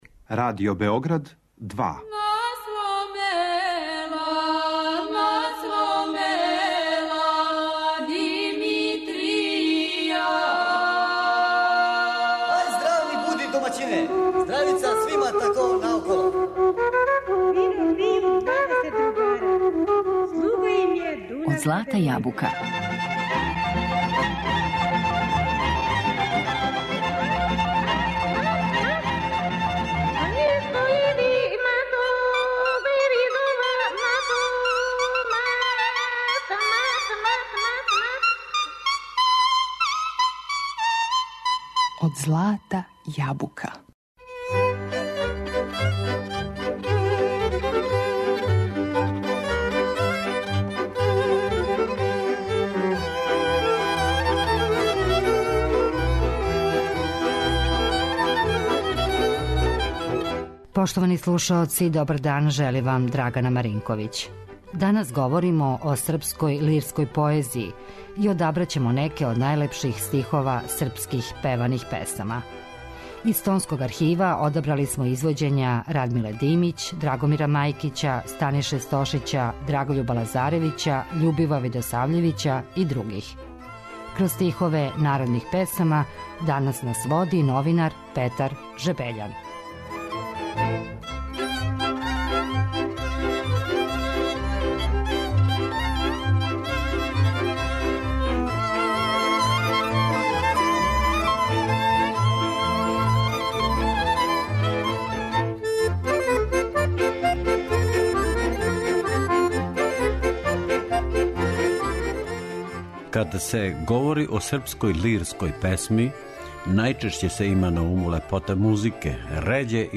Из Тонског архива одабрали смо извођења